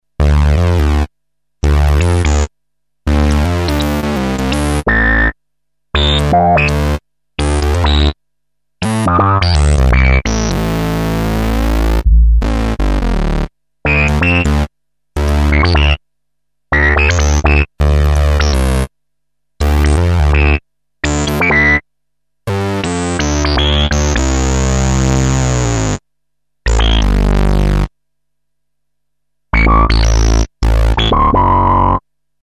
demo bass resonance
demo thunder sound
demo resonant pad
demo Rhodes piano